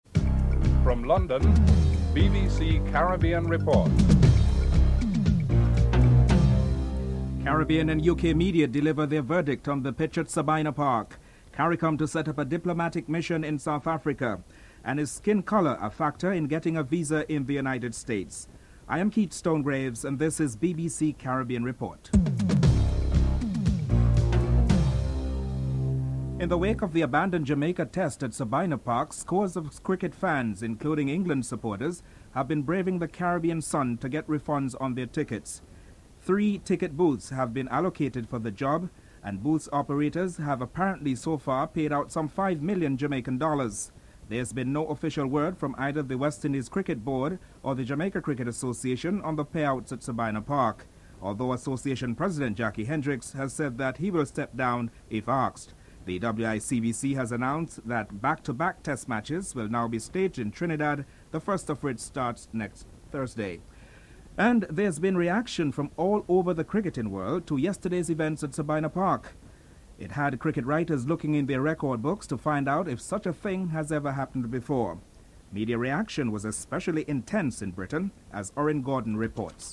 CARICOM has announced plans to set up a diplomatic mission in South Africa. Grenada's Foreign Minister Dr. Raphael Fletcher comments on the trade prospects between the countries.
7. Recap of top stories (14:37-15:00)